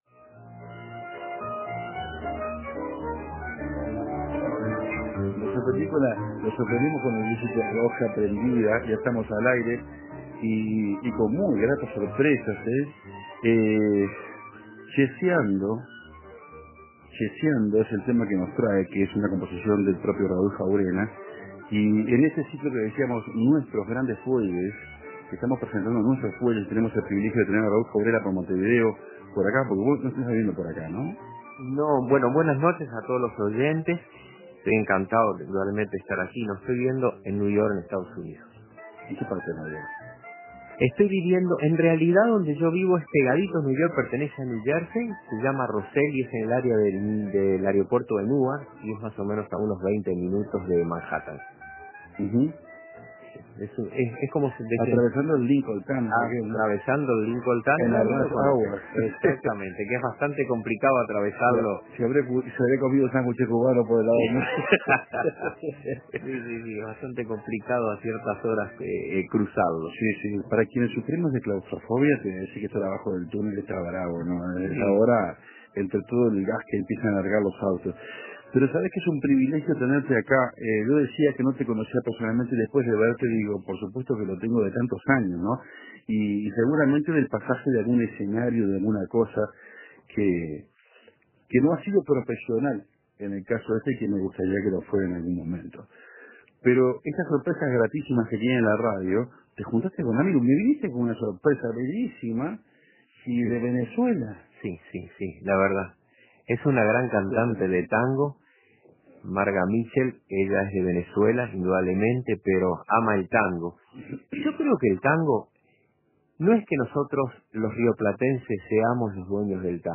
Sigue el ciclo de bandoneones uruguayos
Llegó a Café Torrado, como quién llega a la mesa de un bistró... e invitó a dos amigos: una venezolana y un argentino que cantan tango. Fonoplatea de corte, quebrada, de ¡padre y señor mío!